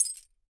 GLASS_Fragment_05_mono.wav